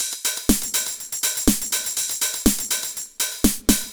Beat 07 No Kick (122BPM).wav